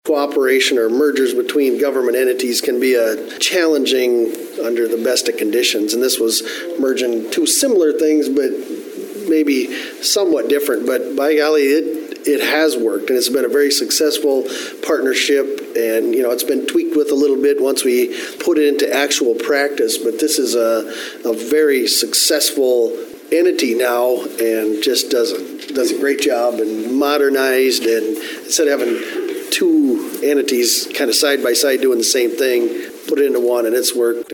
City Commissioner Jamie Huizenga says putting the initial agreement together took a lot of time and collaboration.